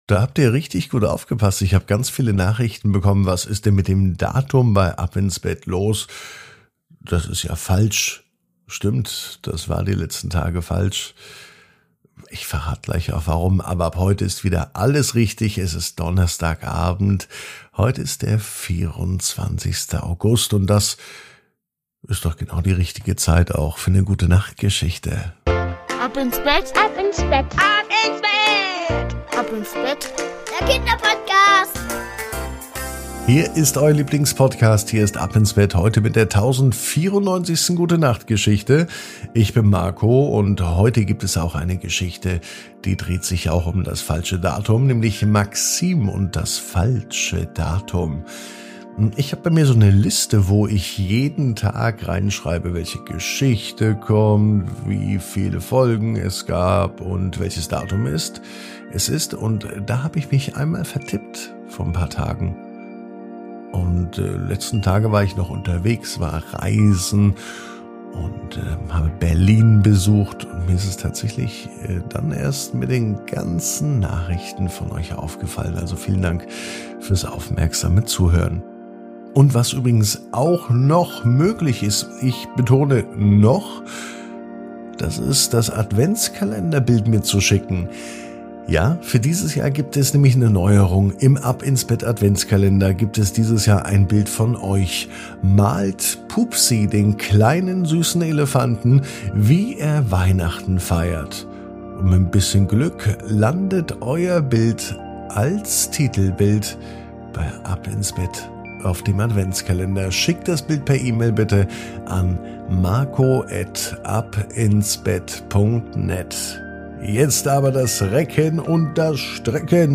Die Gute Nacht Geschichte für Donnerstag